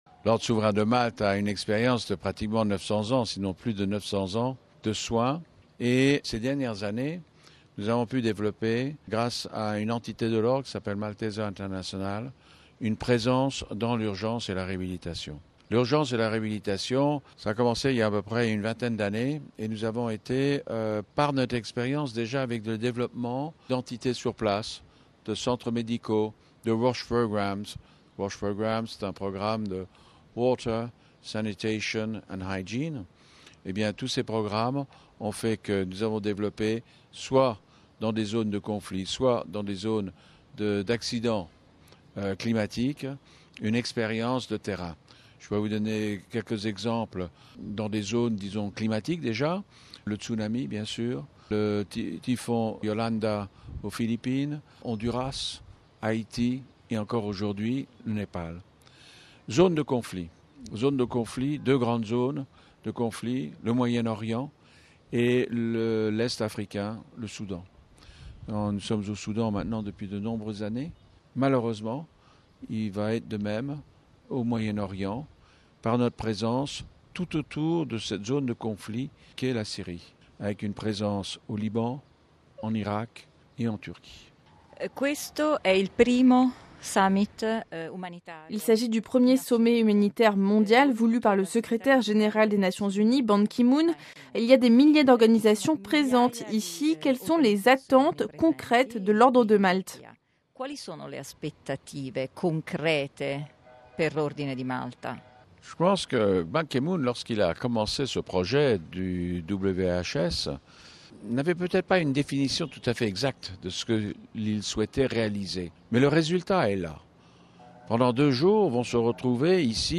(RV) Entretien - L’Église catholique dans son ensemble, participe au sommet humanitaire mondial qui a débuté ce lundi 23 mai 2016 à Istanbul. Outre la Caritas Internationalis, qui dépend directement du Saint-Siège, l’Ordre de Malte est également présent.